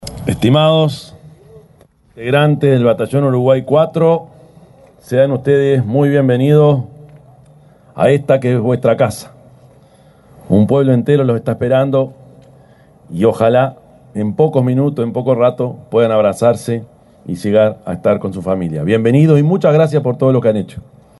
Palabras del presidente Yamandú Orsi al recibir a contingente que regresó del Congo